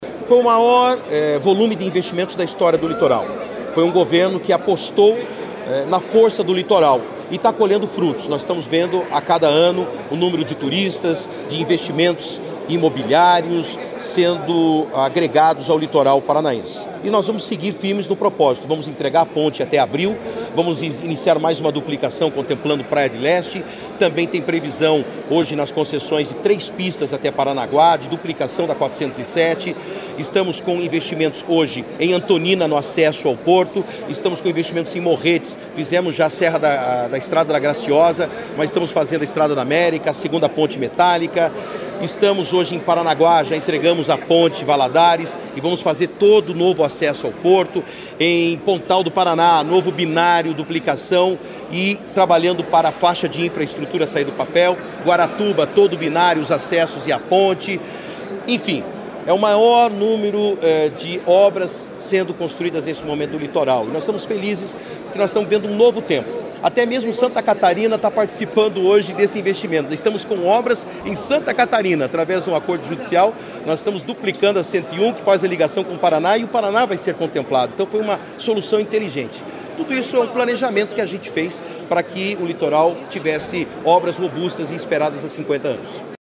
Sonora do secretário de Infraestrutura e Logística, Sandro Alex, sobre os investimentos no litoral paranaense